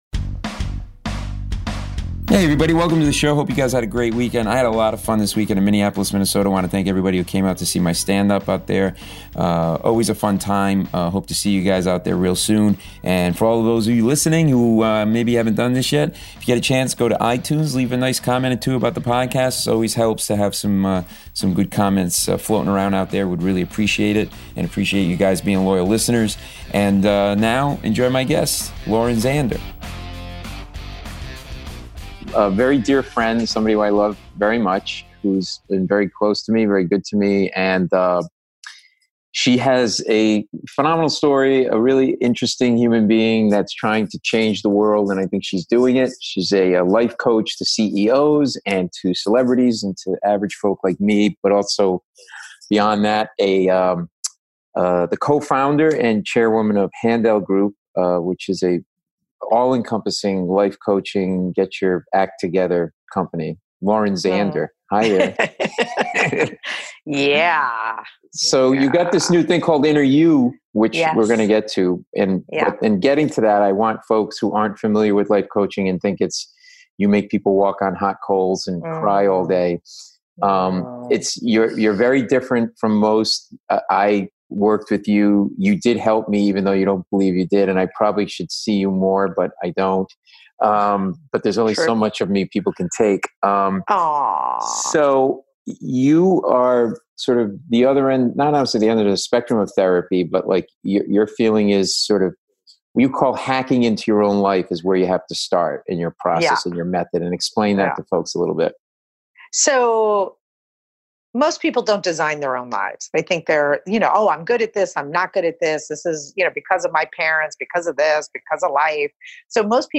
In the interview